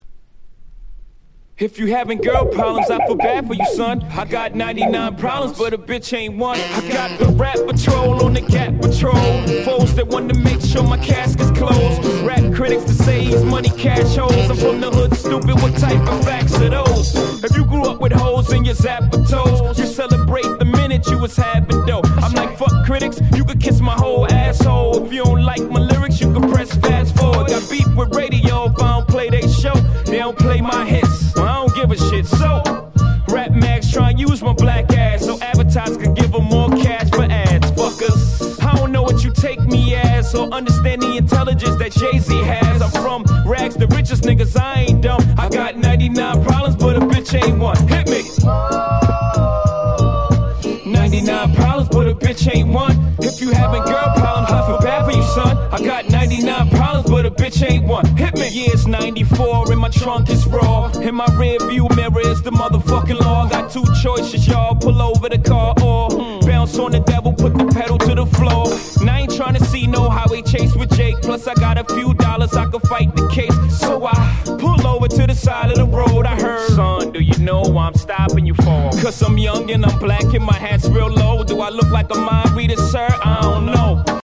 10' Nice Mush Up LP !!